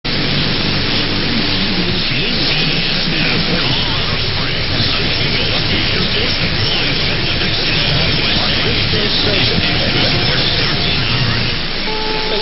Twin Cities AM DX log